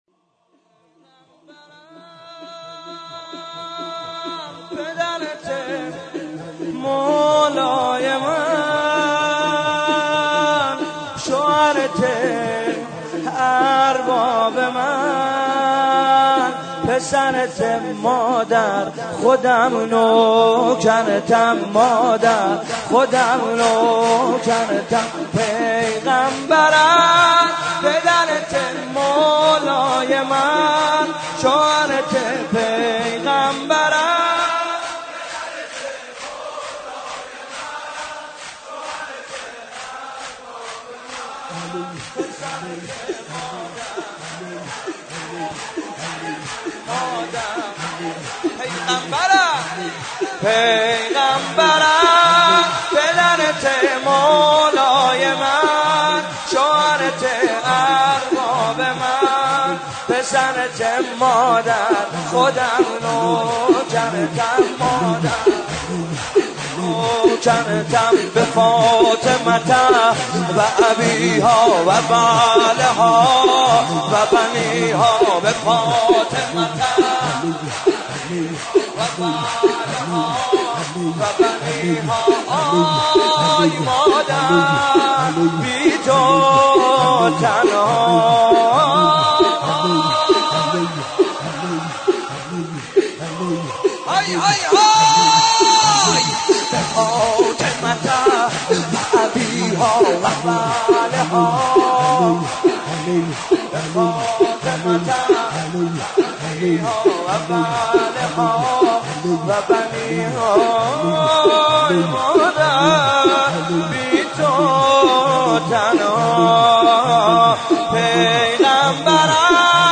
سینه زنی شور